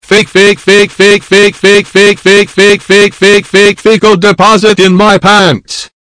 Play, download and share Fecal Deposit 2.0 original sound button!!!!
fecal-deposit-2-0.mp3